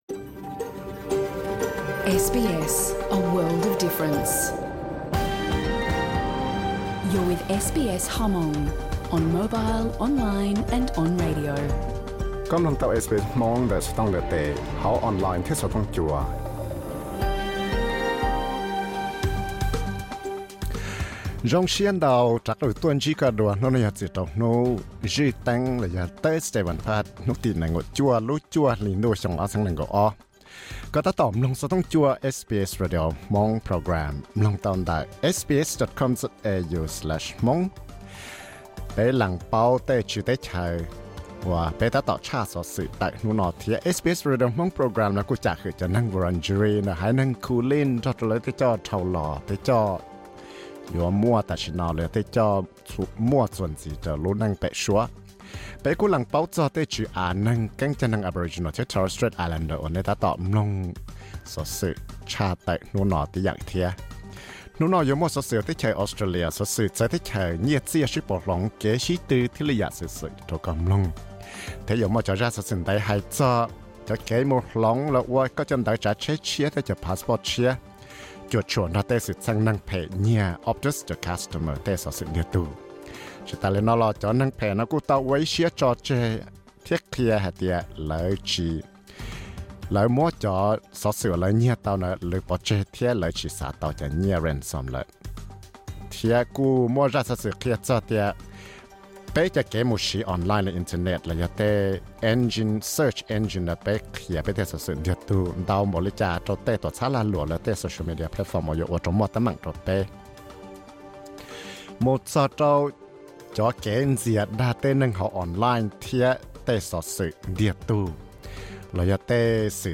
Program xov xwm tshaj tawm rau hnub zwj Teeb (Thursday news program 29.09.2022): 1) Hloov ID thiab passport yog koj tej xov xwm ntiag tug raug neeg phem nyiag ntawm lub tuam txhab Optus. 2) Peb qhia peb tej xov xwm ntau npaum li cas rau lwm tus hauv online.